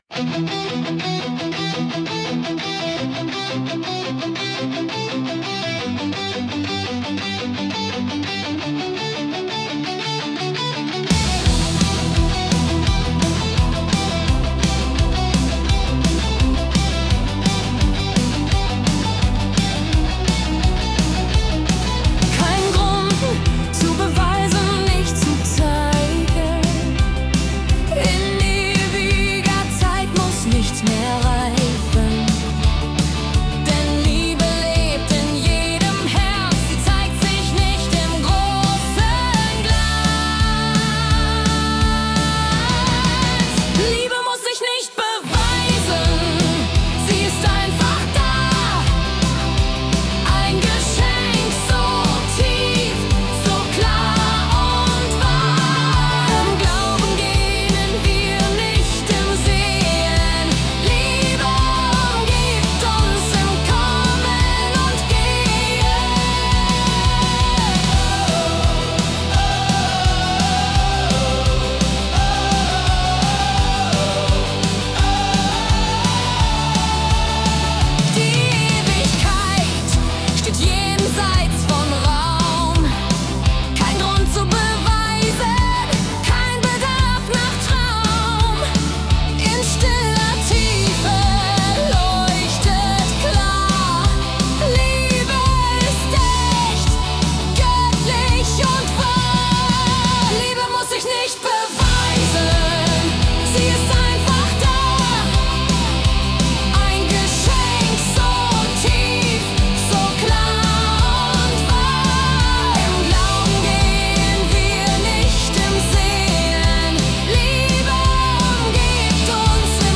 Melodic Heavy MetalBPM ~148